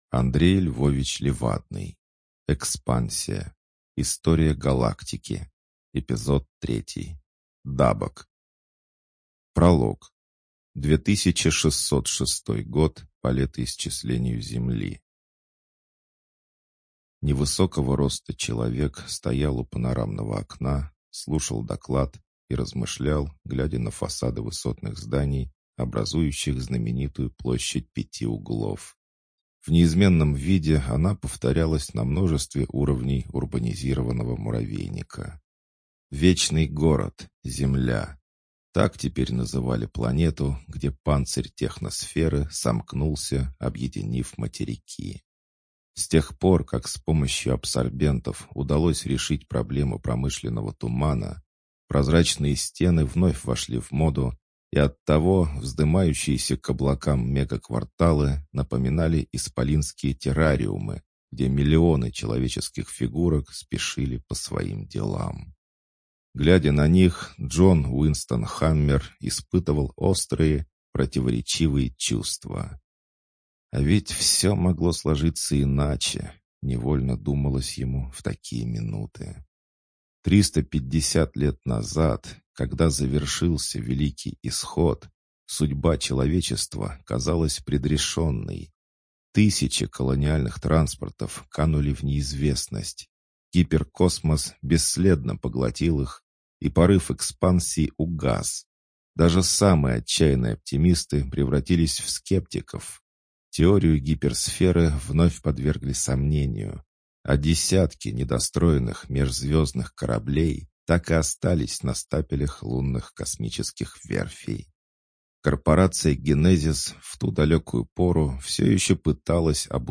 Жанр: боевая фантастика